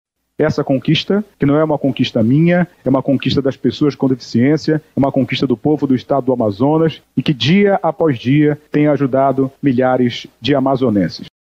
Na tribuna da Casa, destacou que houve a solicitação de 9500 carteiras de identificação das pessoas com deficiência, destas, 4820 emitidas só na capital.
Sonora-Alvaro-Campelo-–-deputado-estadual.mp3